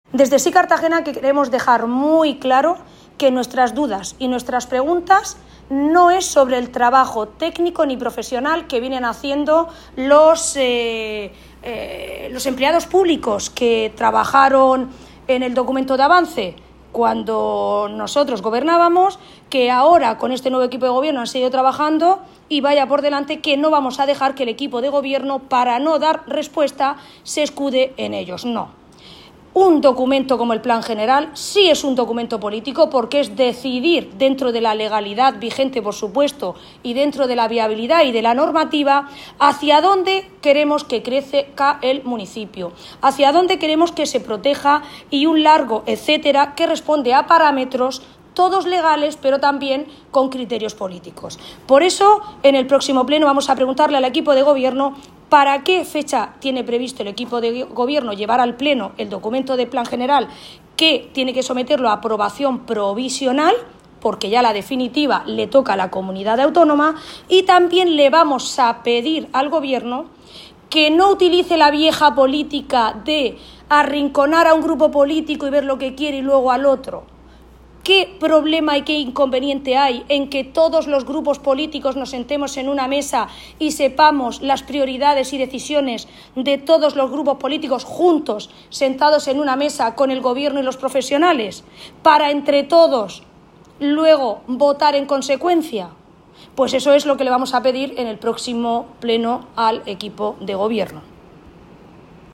Audio: Declaraciones de Ana Bel�n Castej�n, S� Cartagena (I) (MP3 - 1,10 MB)